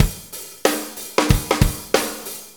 Shuffle Loop 28-12.wav